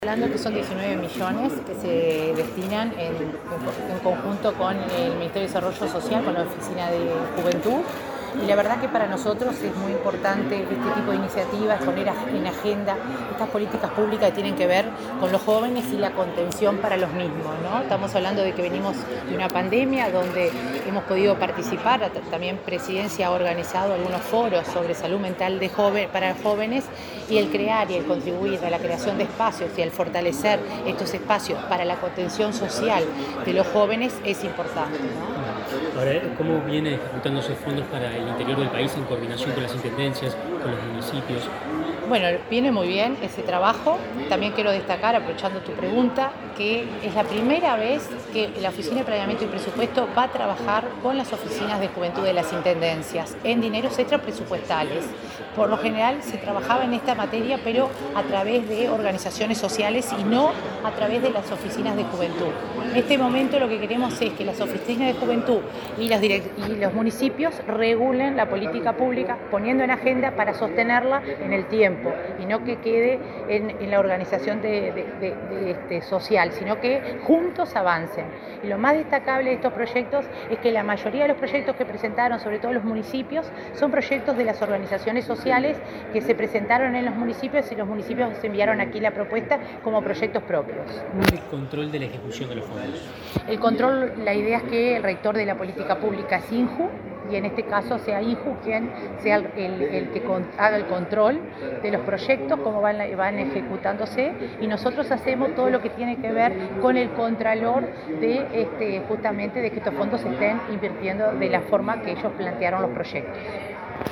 Declaraciones de la coordinadora de Descentralización de OPP, María de Lima
La coordinadora de Descentralización de la Oficina de Planeamiento y Presupuesto (OPP), María de Lima, dialogó con la prensa antes de participar en la firma de un acuerdo junto con el Instituto Nacional de la Juventud (INJU) y gobiernos departamentales y municipales, en el marco del programa Fondo Juventud. El acto se realizó en la Torre Ejecutiva.